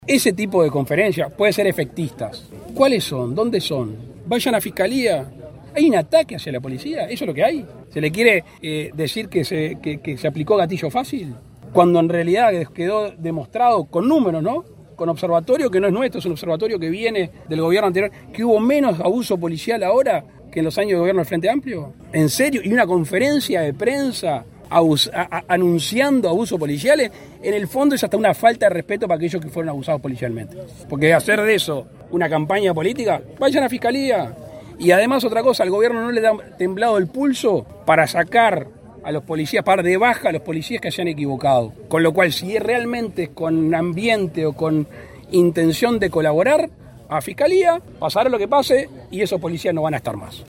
El presidente de la República, Luis Lacalle Pou, fue consultado en Soriano, donde participó de la Expo Activa, sobre la presentación pública que realizó el Frente Amplio de 50 casos de abuso policial.